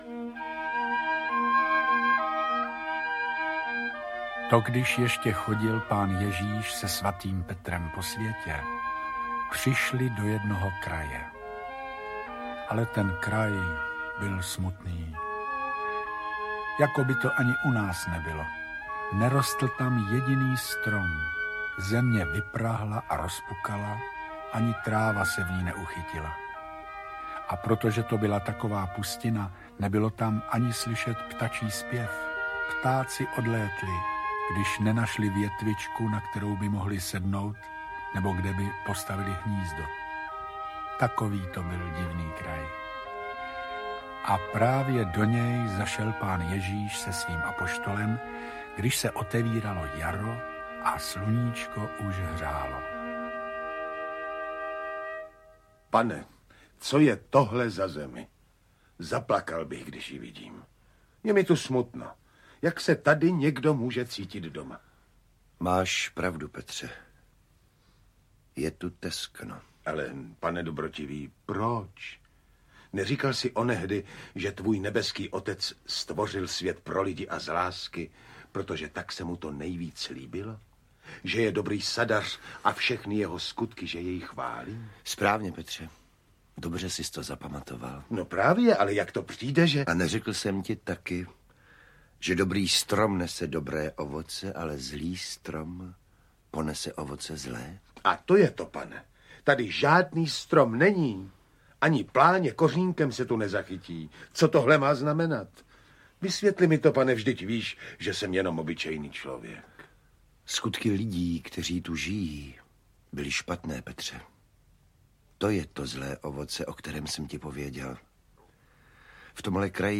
Audio knihaKdyž ještě chodil Pán Ježíš s Petrem po světě
Ukázka z knihy